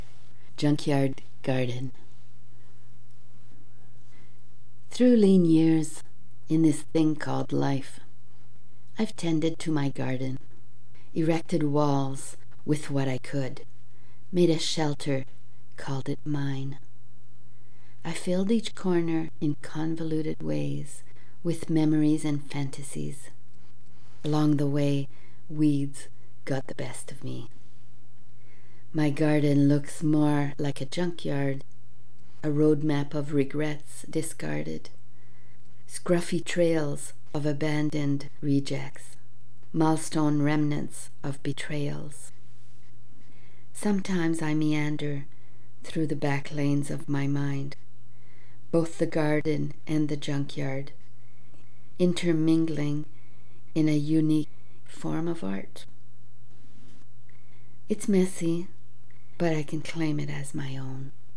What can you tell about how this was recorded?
Read on air by invitation ~ January 16, 2022 'POETS HARBOUR'